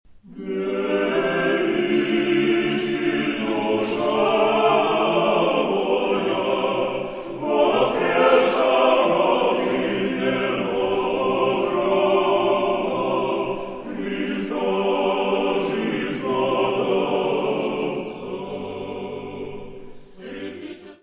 Descrição Física:  1 Disco (CD) (55 min.) : stereo; 12 cm
Área:  Música Clássica